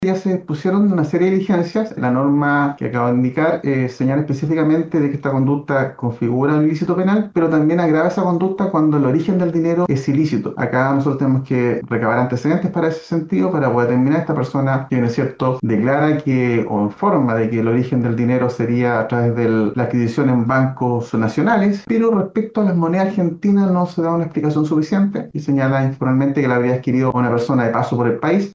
En tanto el Fiscal Adjunto de la Fiscalía de San Antonio, Rolando Silva, señaló que se están realizando una serie de diligencias para determinar el origen de las monedas argentinas.